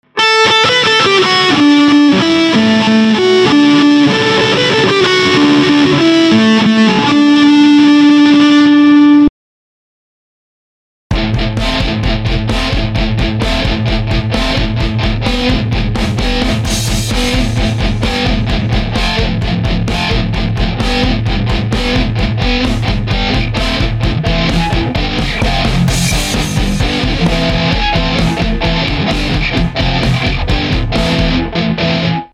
Починил свою электруху, только она теперь САТАНИЧЕСКИ фонит, срет, пердит :gg: